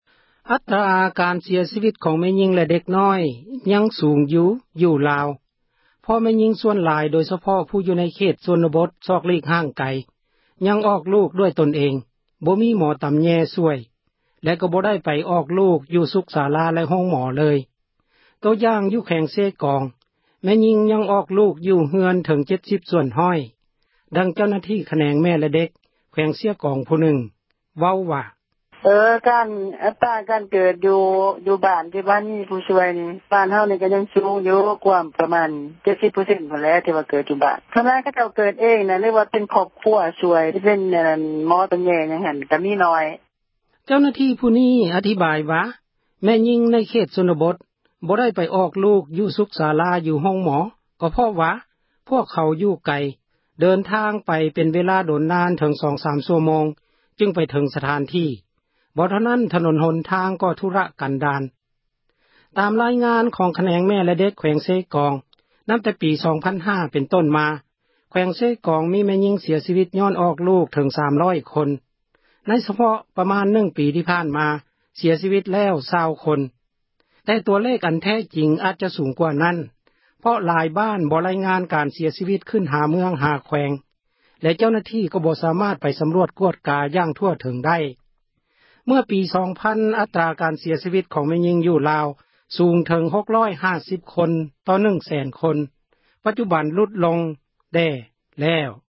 ເຈົ້າໜ້າທີ່ ຂແນງ ແມ່ແລະເດັກ ແຂວງເຊກອງ ຜູ້ນຶ່ງເວົ້າວ່າ: